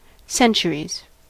Ääntäminen
Synonyymit eternity Ääntäminen US : IPA : [ˈsɛn.tʃə.ɹiz] Lyhenteet ja supistumat CC.